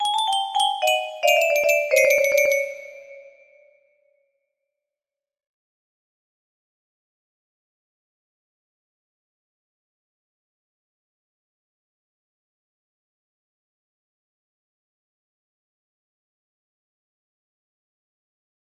Pretty Fields 2 music box melody